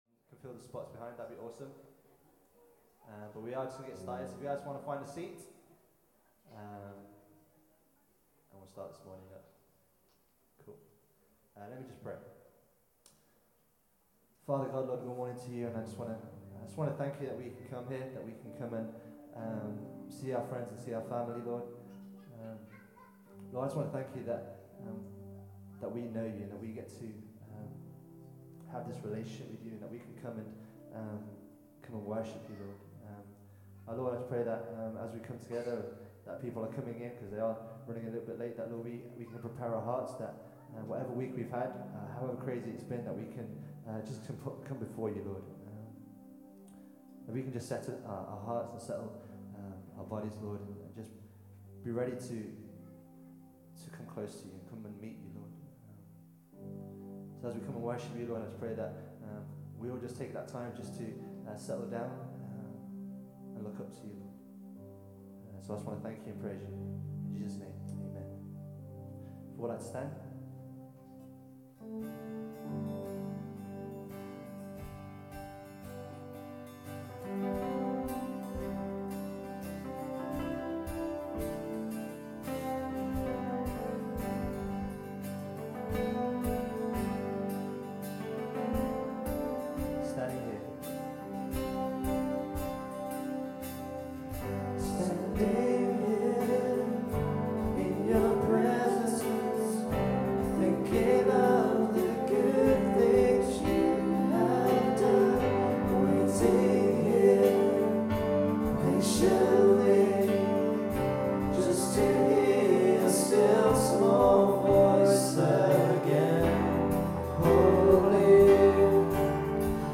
October 19 – Worship